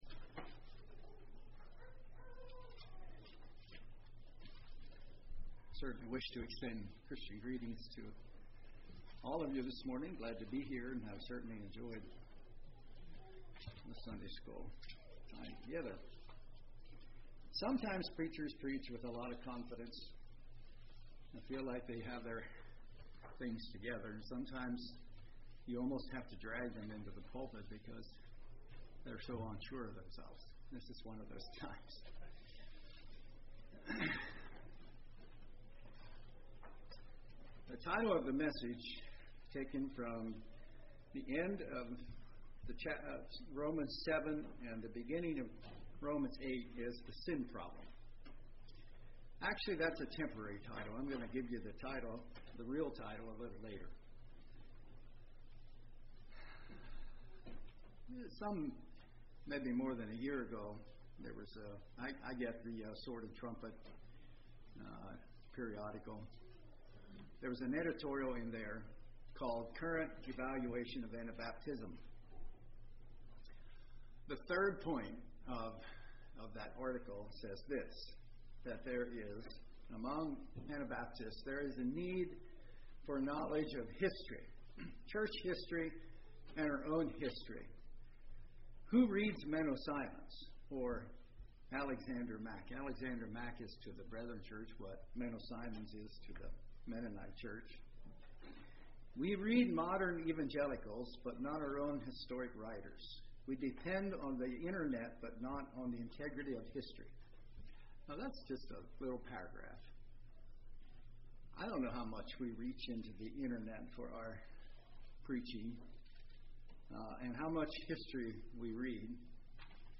Congregation: Pensacola
Sermon